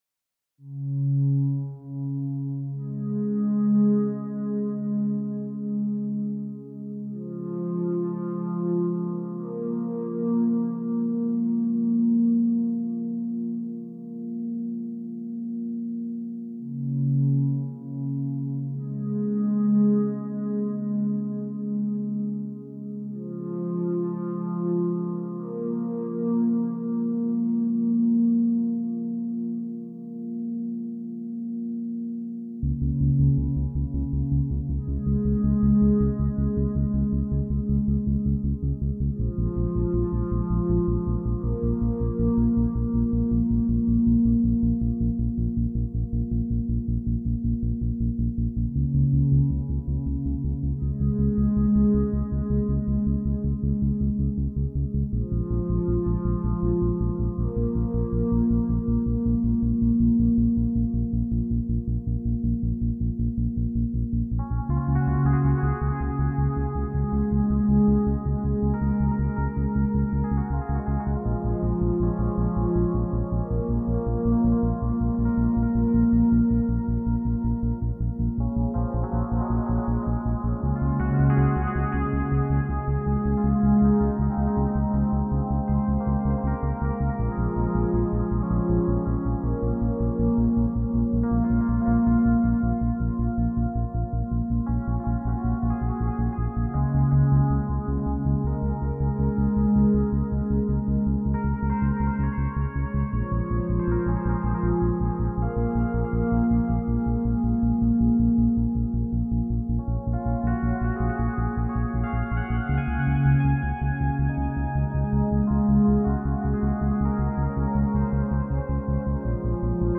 Musik: